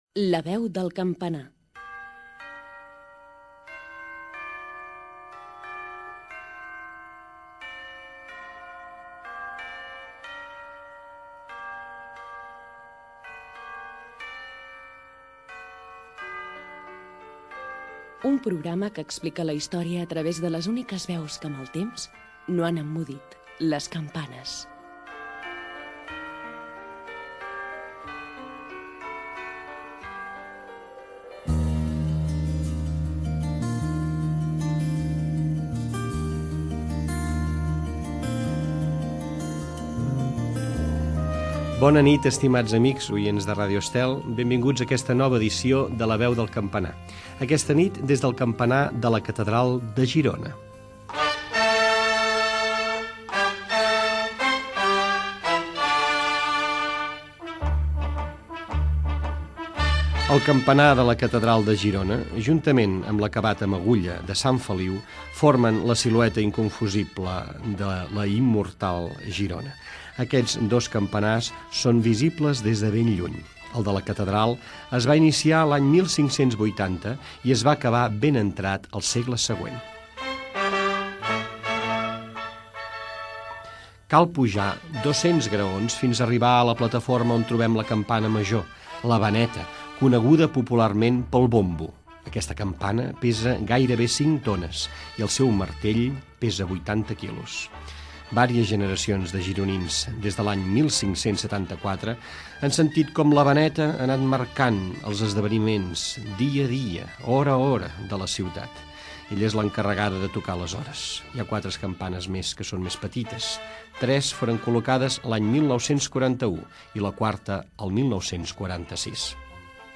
Careta del programa, espai dedicat al campanar de la catedral de Girona on hi ha la Beneta
Banda FM